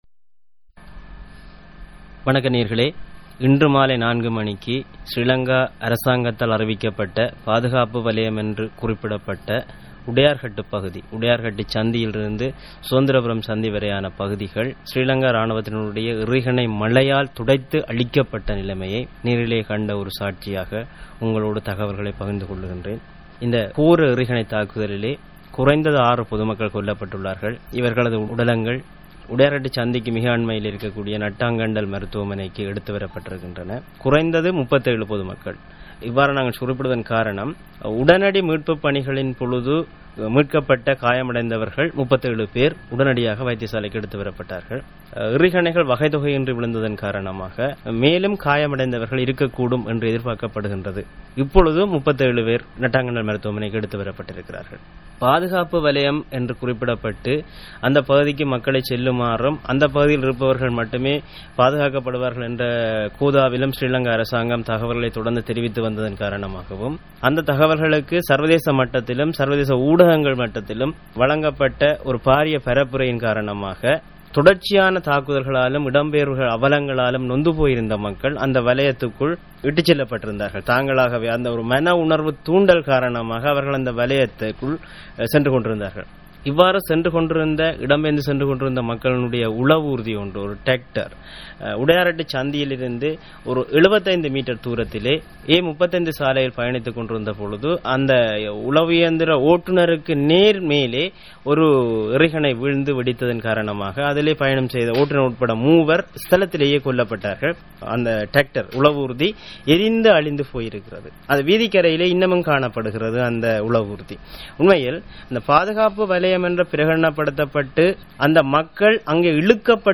Voice: Audio report